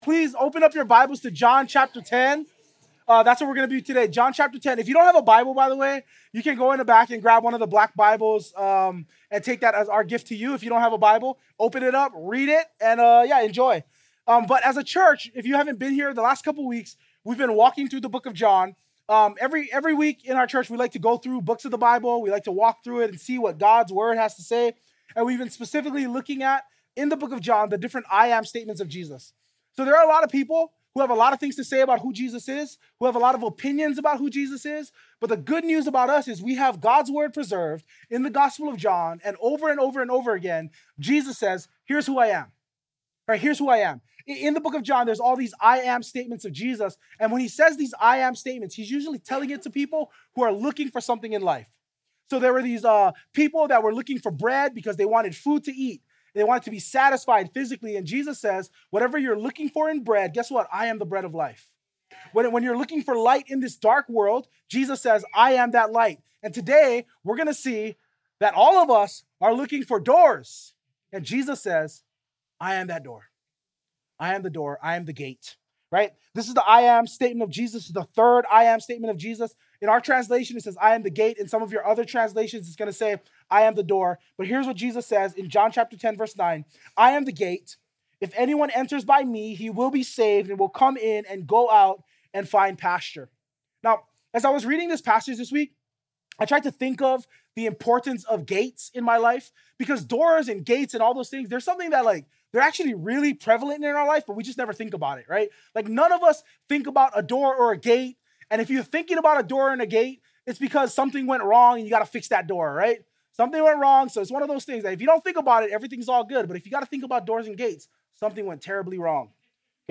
The Access You’re Looking For | Harbor Church Waipahu